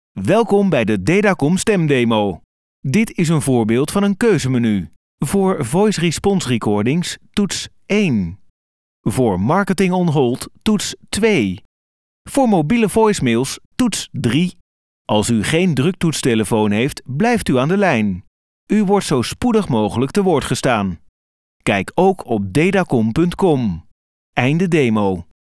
Beluister hier enkele voorbeelden van onze professionele voice over stemartiesten.